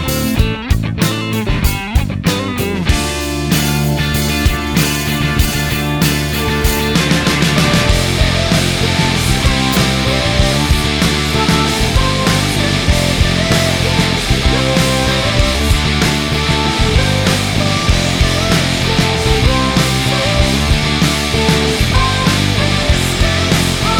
End Solo Cut Down Rock 4:15 Buy £1.50